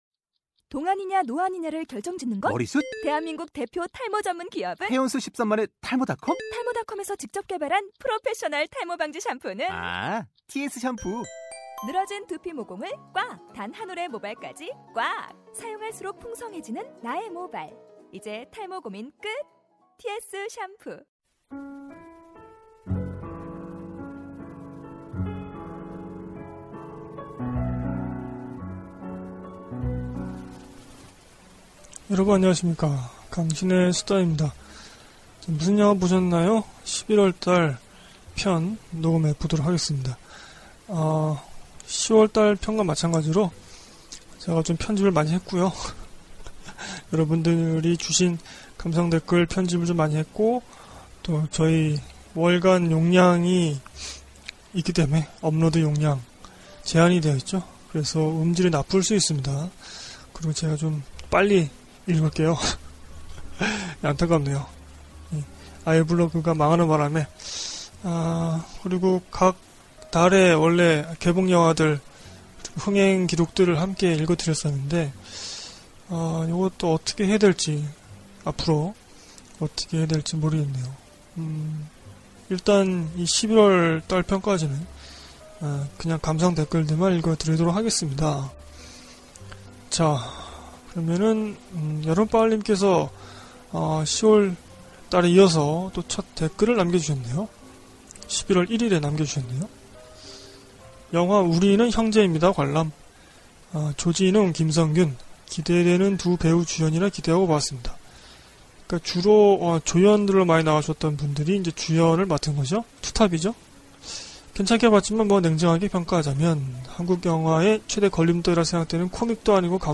여러분이 주신 감상댓글만 읽었습니다. 용량 제약(시간 제약) 때문에 빠르게 읽느라 발음이 뭉개지고, 청취자분들의 글을 많이 축약했어요. 또 파일을 다운그레이드하여 음질이 안 좋을 수 있습니다.